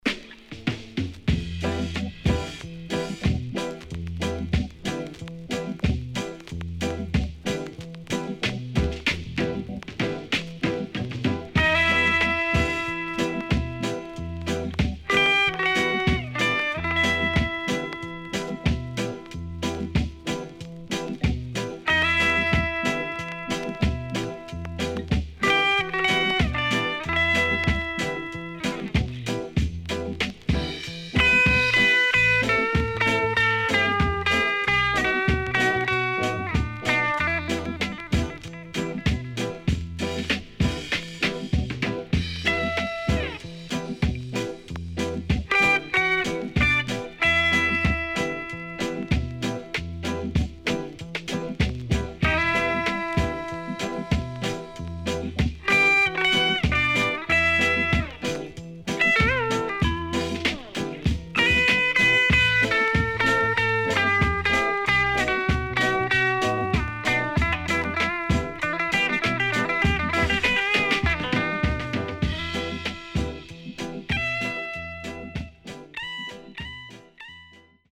CONDITION SIDE A:VG(OK)〜VG+
SIDE A:所々チリノイズがあり、少しプチノイズ入ります。